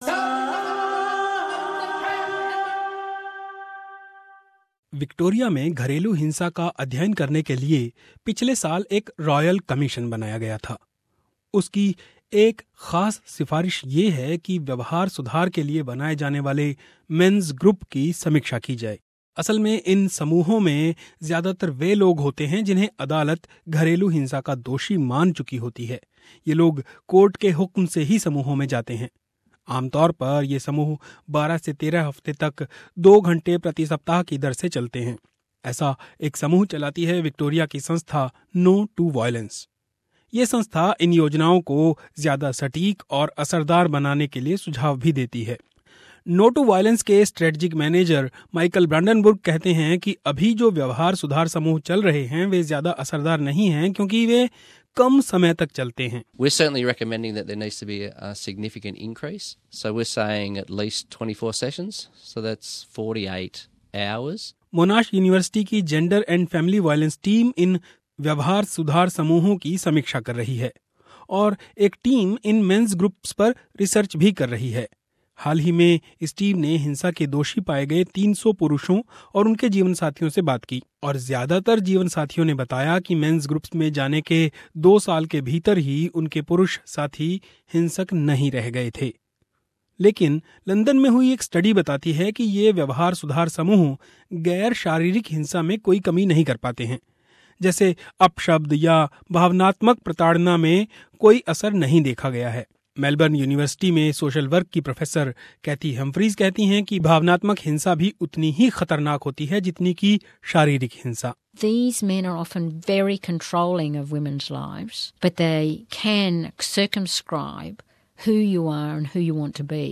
SBS हिन्दी